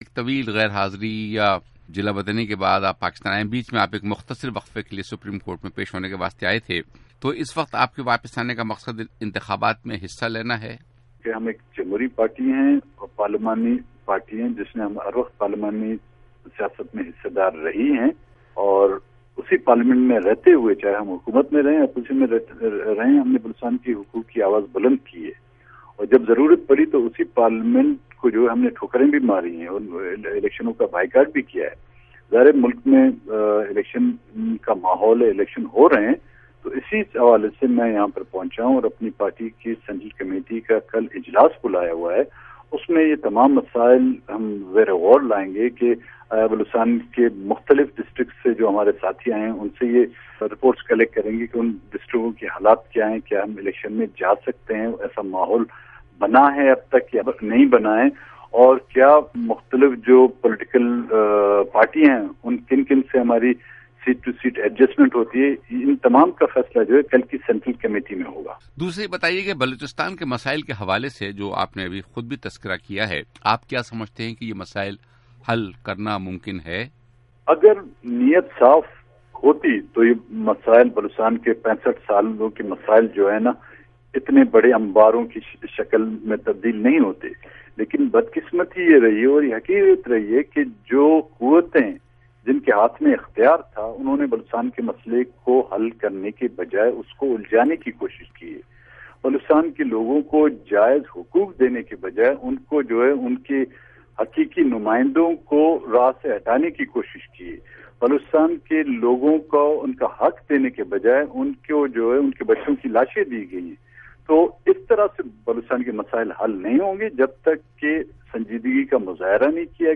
اختر مینگل کا انٹرویو